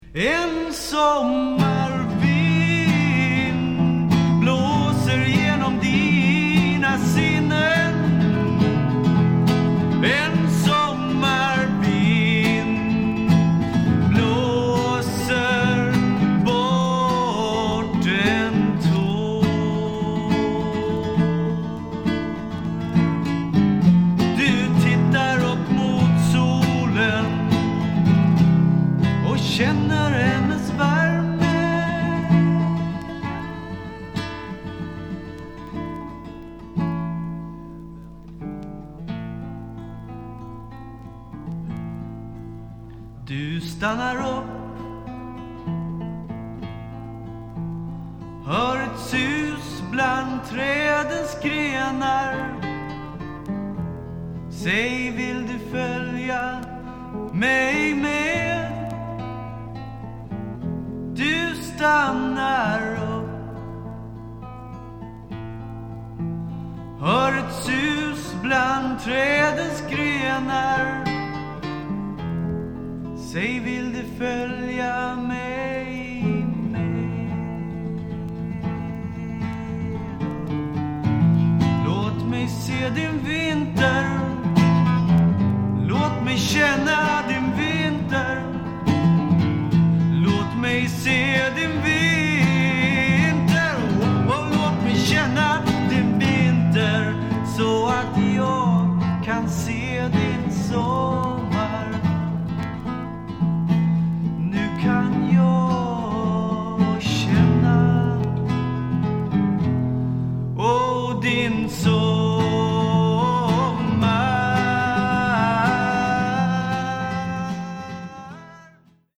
En melankoliskt klingande ballad som sin ton till trots